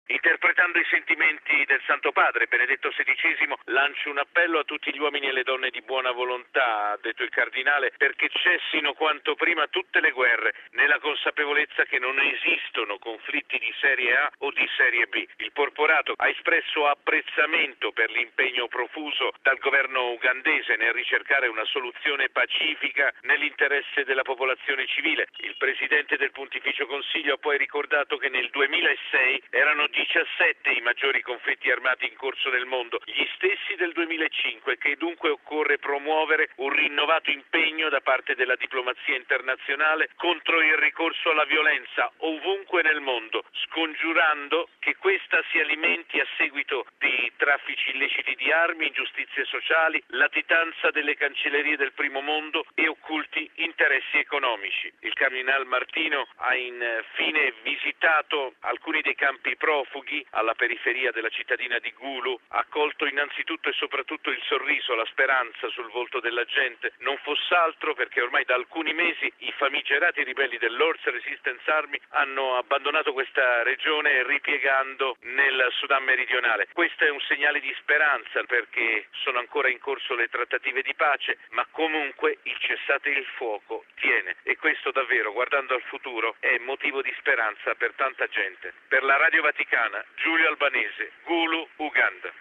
Il porporato ha anche invitato la diplomazia internazionale ad un impegno più forte contro il ricorso alla violenza. Il servizio